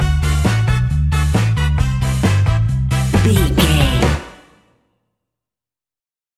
Classic reggae music with that skank bounce reggae feeling.
Aeolian/Minor
dub
laid back
chilled
off beat
drums
skank guitar
hammond organ
percussion
horns